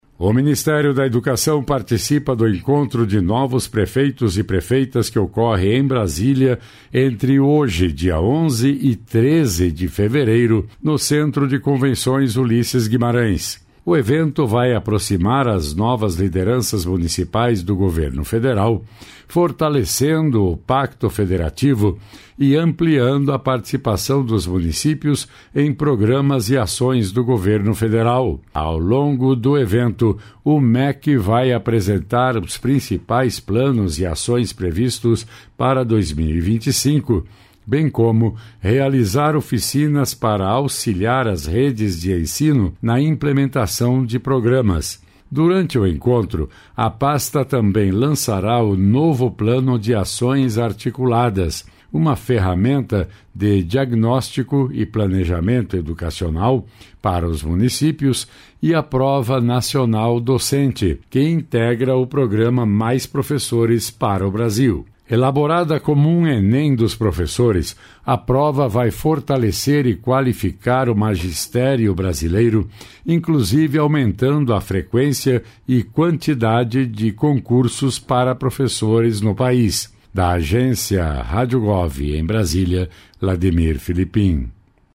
Outras Notícias